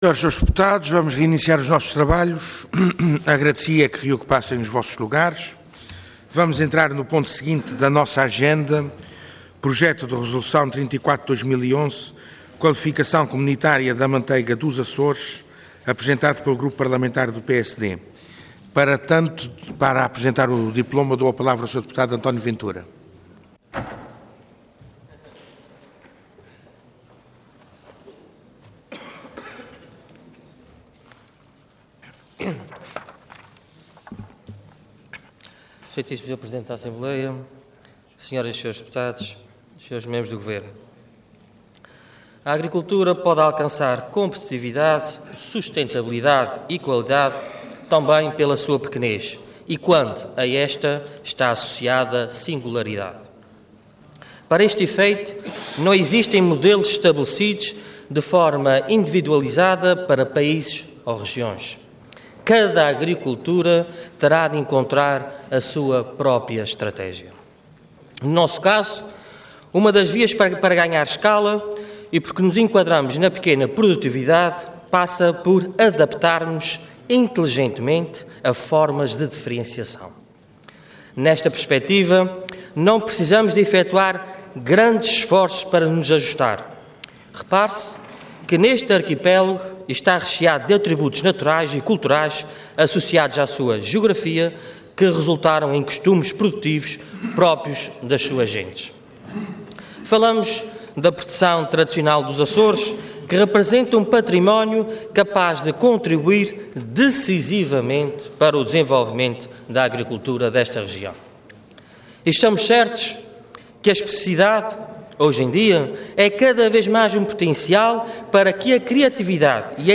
Intervenção Projeto de Resolução Orador António Ventura Cargo Deputado Entidade PSD